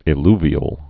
(ĭ-lvē-əl)